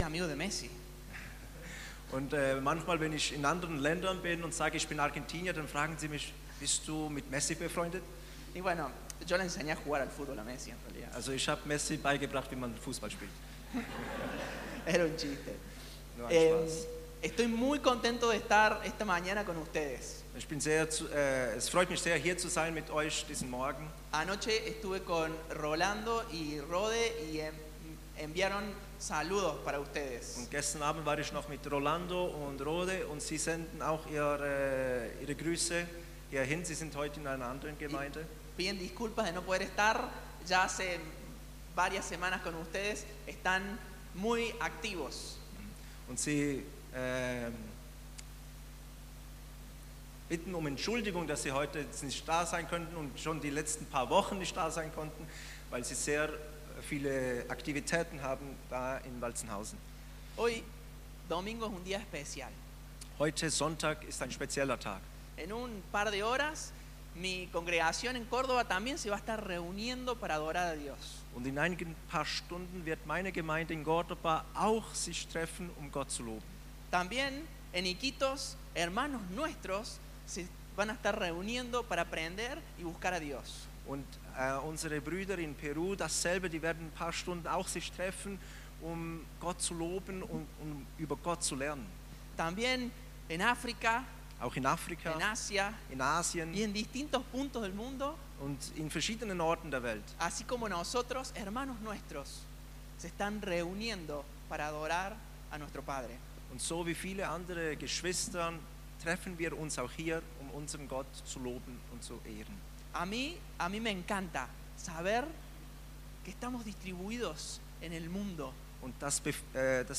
Mai 2024 Movida über Evangelisation Prediger/in: Movida Mitarbeiter « Pfingsten Pfingsten » Kommentar absenden Antworten abbrechen Deine E-Mail-Adresse wird nicht veröffentlicht.